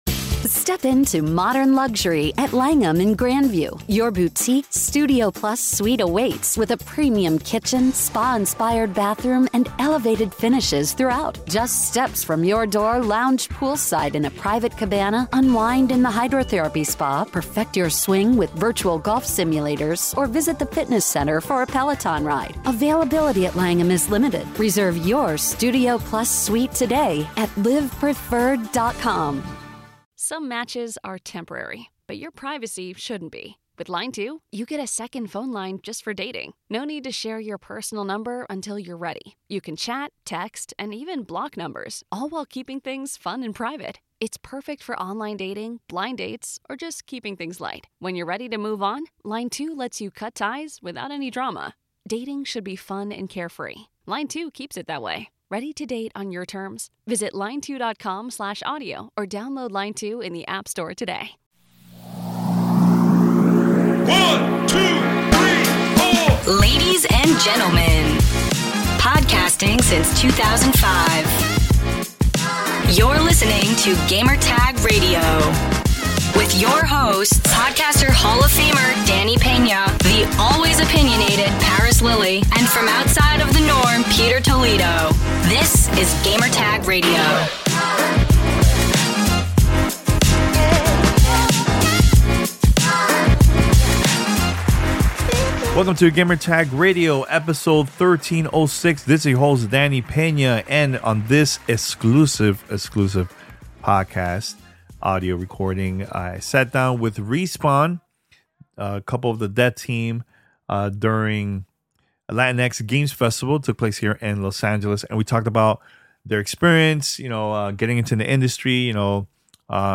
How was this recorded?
that took place during the 5th annual Latinx Games Festival 2023 in Los Angeles, CA.